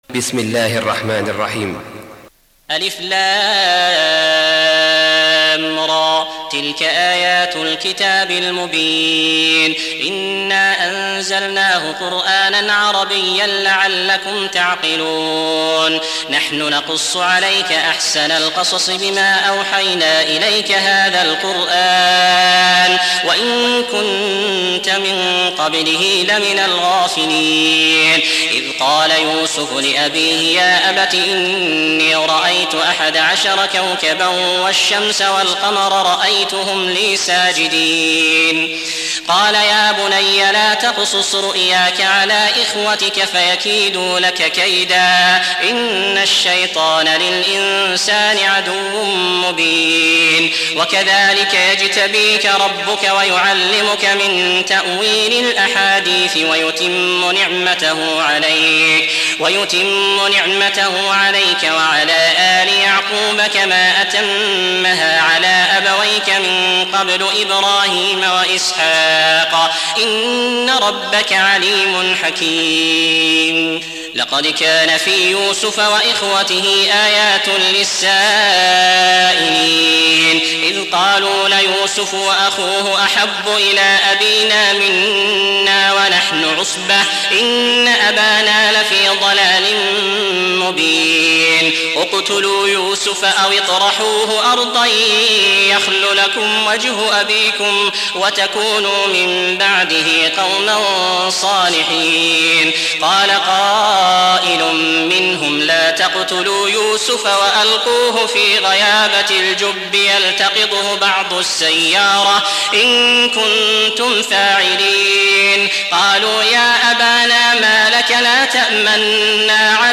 Surah Repeating تكرار السورة Download Surah حمّل السورة Reciting Murattalah Audio for 12. Surah Y�suf سورة يوسف N.B *Surah Includes Al-Basmalah Reciters Sequents تتابع التلاوات Reciters Repeats تكرار التلاوات